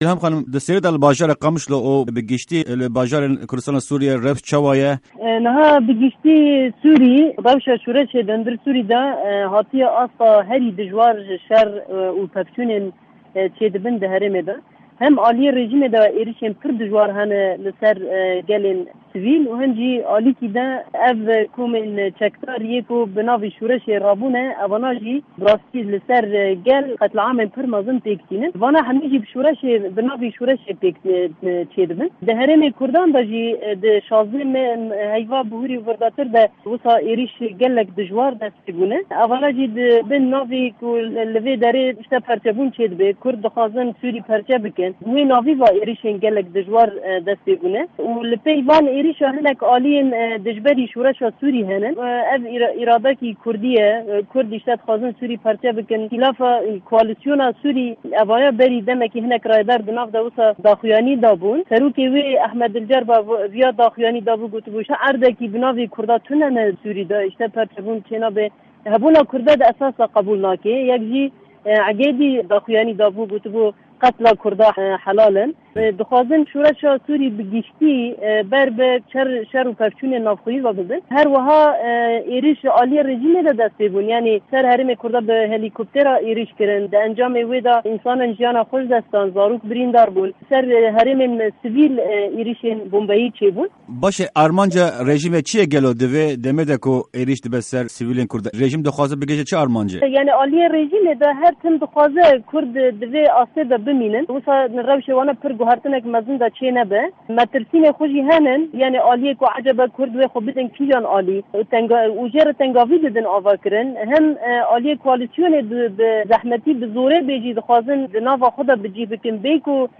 Di hevpeyvîna Dengê Amerîka de Îlham Ahmed, endama Desteya Bilind a Kurd û rêvebirîya TEV-DEM'ê rewşa heyî dinirxîne.
Hevpeyvîn_Îlham_Ahmed